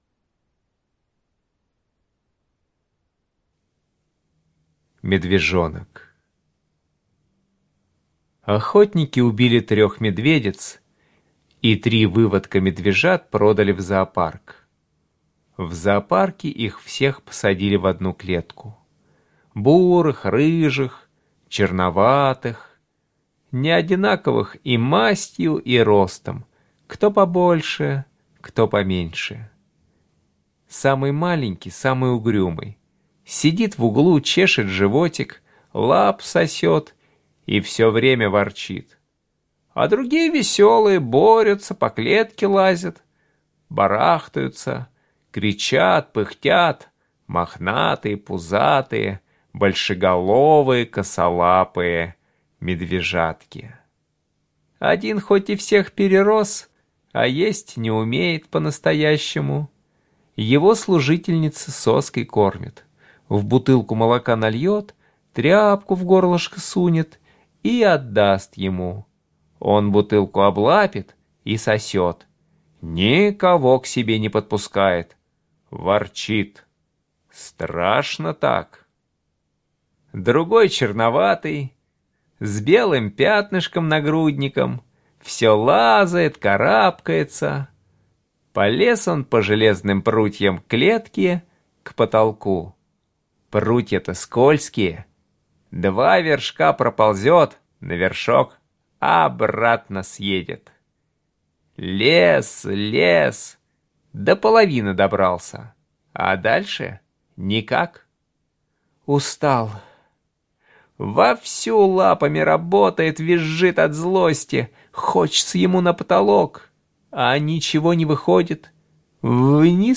Слушайте Медвежонок - аудио рассказ Чарушина Е.И. Охотники убили трех медведиц, а медвежат отдали в зоопарк. Там они познавали премудрости жизни.